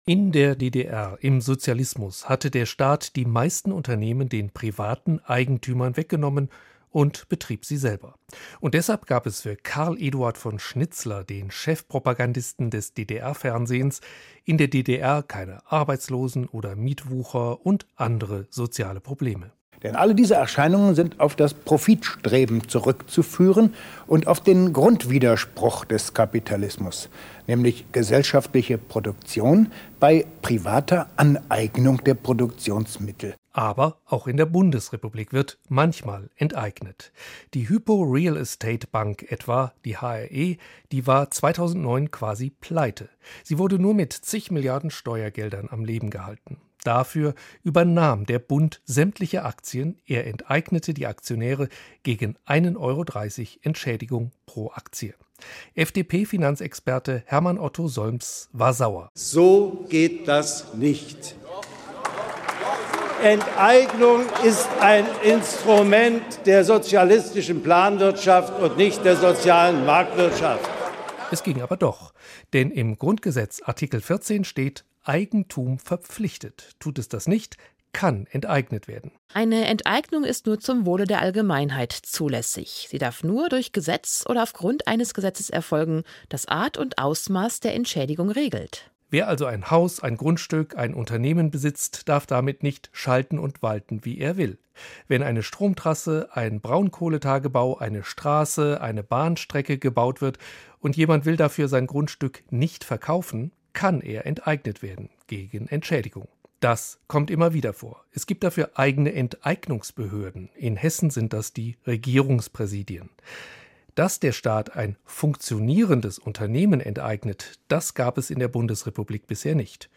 Mein Beitrag heute früh für hr-info: